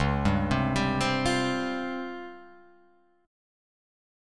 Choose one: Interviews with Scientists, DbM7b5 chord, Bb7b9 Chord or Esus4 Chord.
DbM7b5 chord